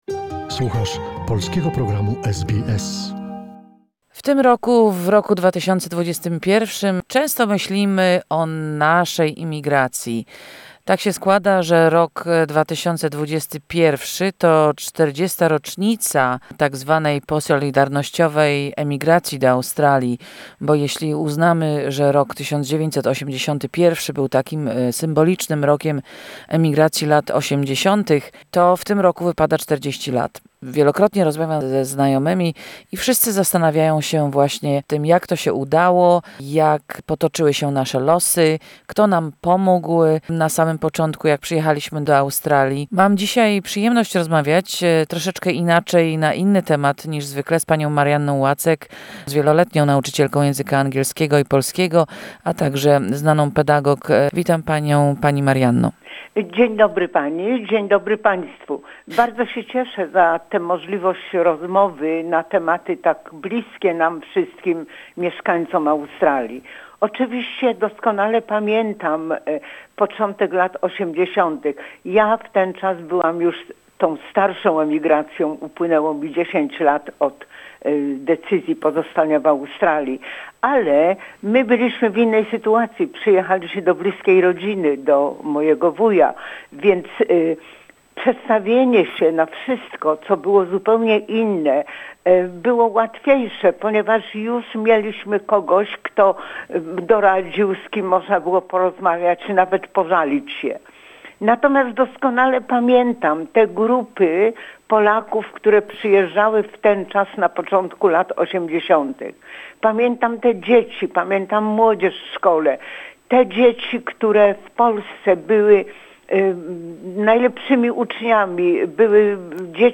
First Part of our radio series on the Polish migration wave of the 1980s.